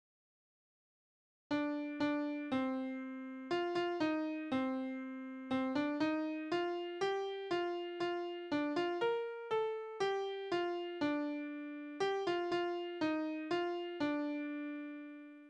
Tonart: B-Dur
Taktart: 4/4
Tonumfang: kleine Septime
Besetzung: vokal